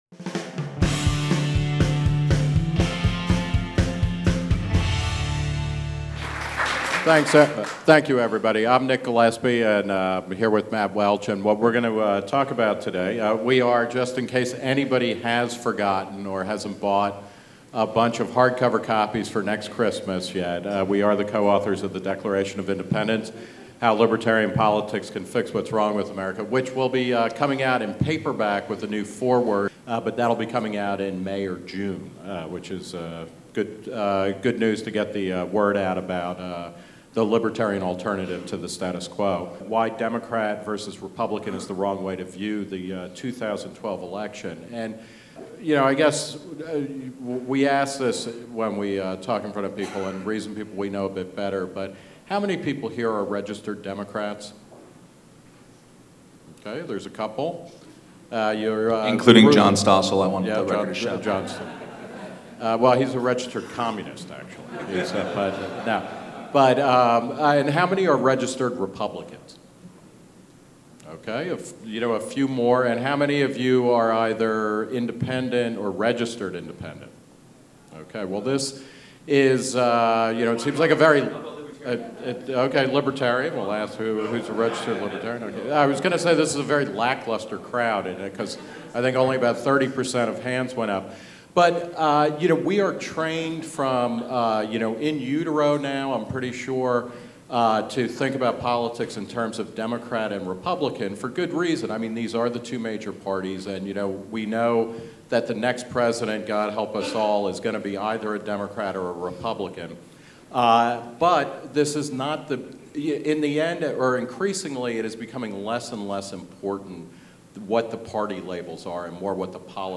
Why Democrat vs. Republican is the Wrong Way to Look at the 2012 Election: Nick Gillespie and Matt Welch at Reason Weekend